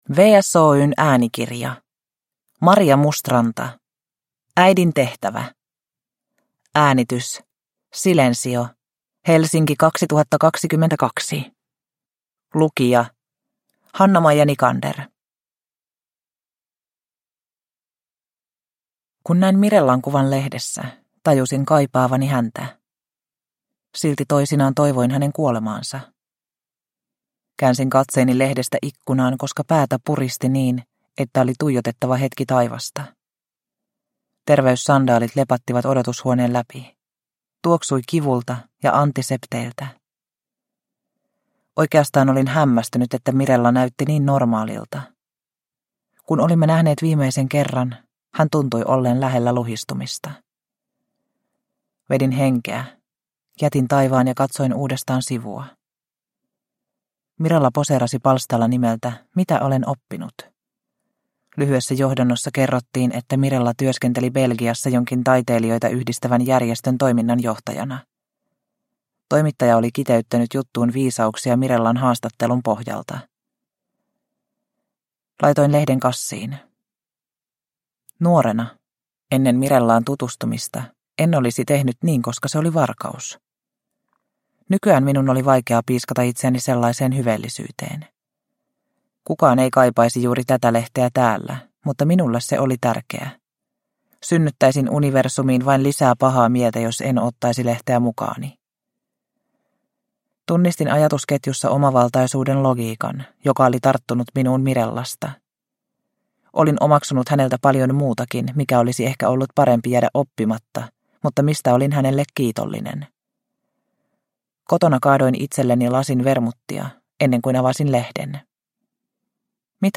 Äidin tehtävä – Ljudbok – Laddas ner